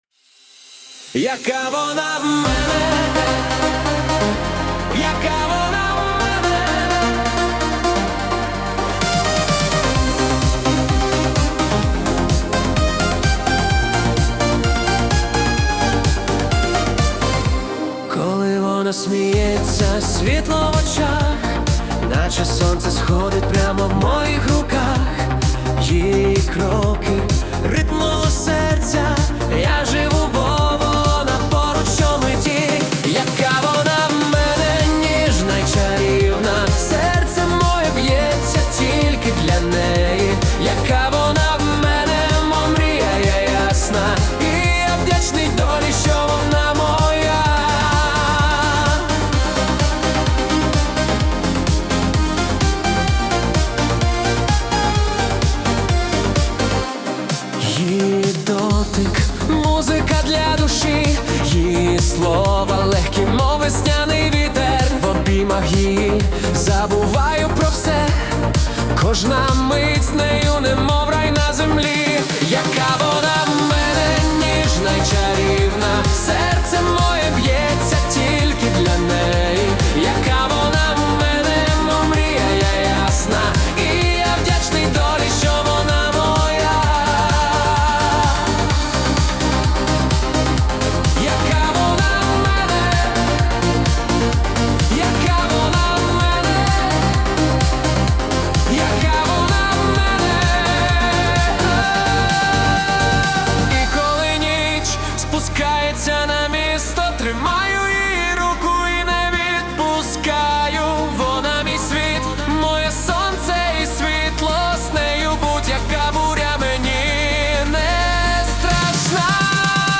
_Yaka_vona_v_mene_Ukrainian_Disco_Pop_2025.mp3